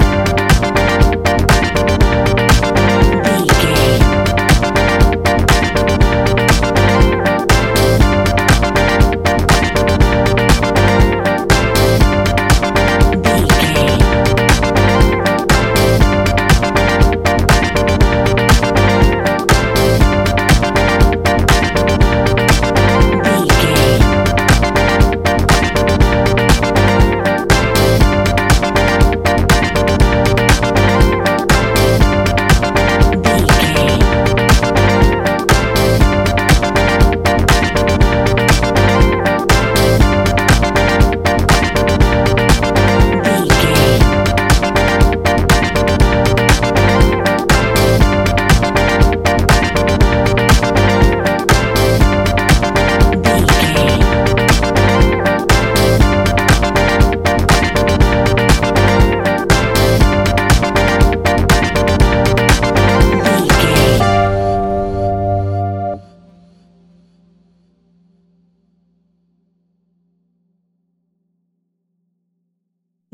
Ionian/Major
laid back
Lounge
sparse
chilled electronica
ambient
atmospheric